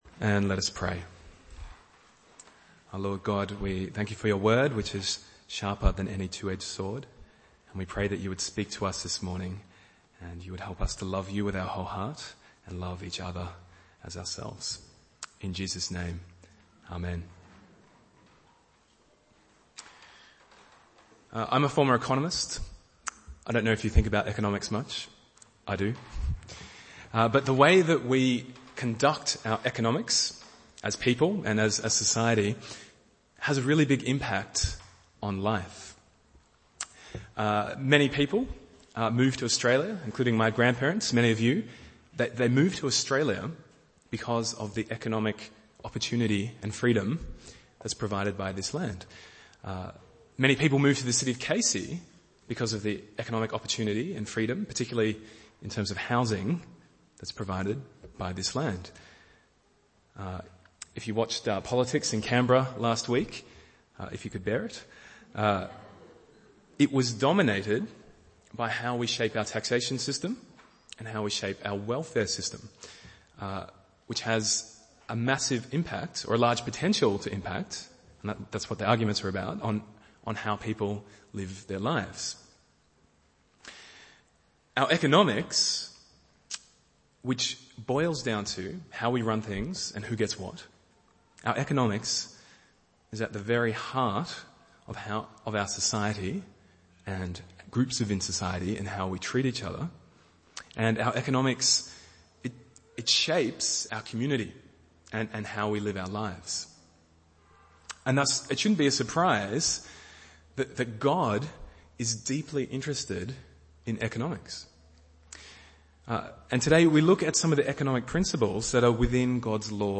Bible Text: Deuteronomy 15:1-11 | Preacher